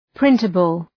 {‘prıntəbəl}